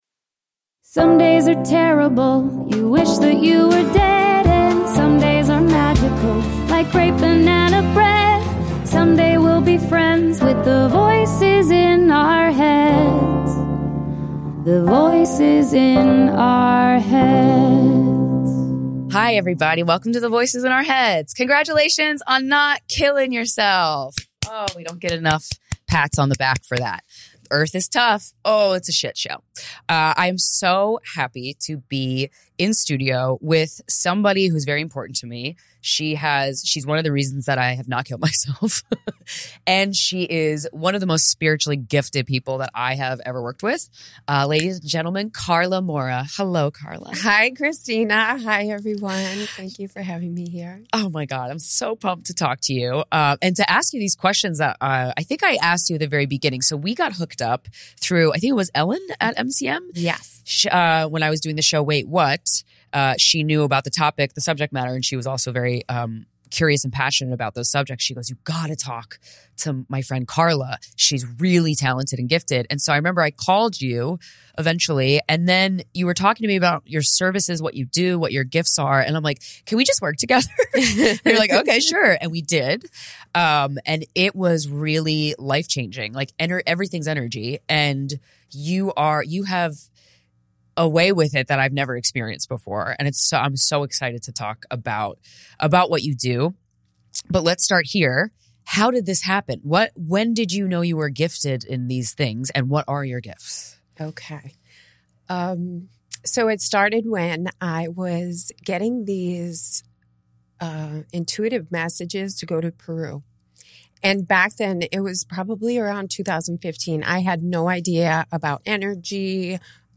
Comedy
I'm so happy to be in studio with somebody who's very important to me.